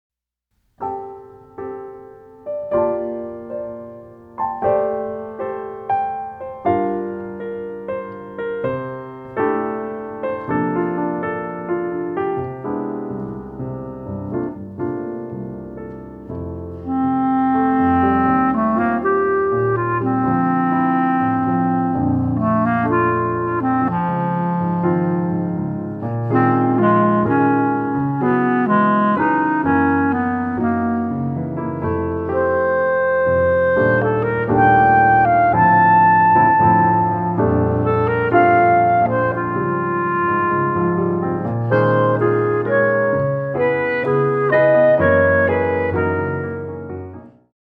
A selection from the opening of this clarinet and piano duo.